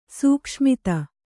♪ sūkṣmita